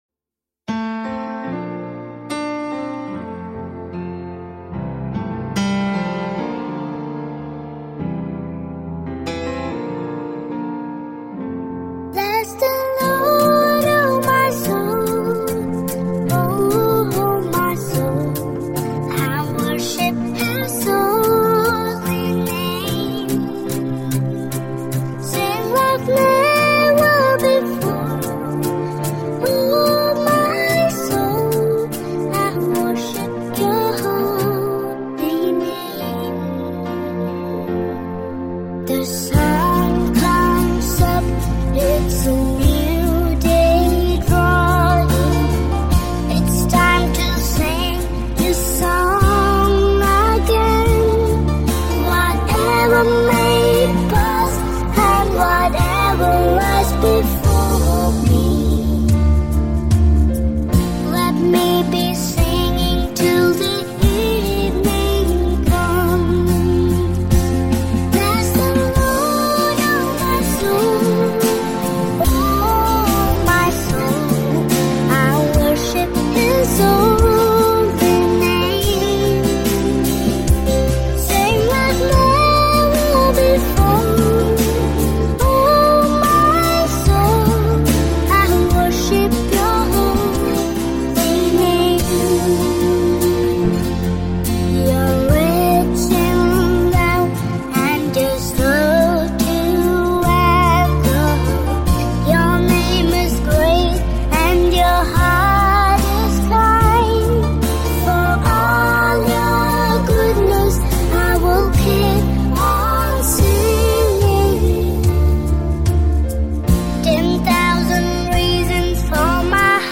*Converted for key of C instrument